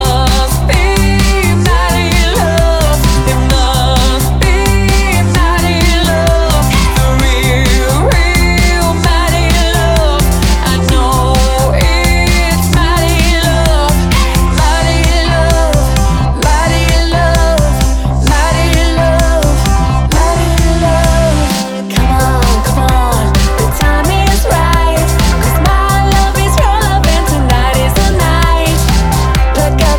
poppig frischen Sound auf die Tanzfläche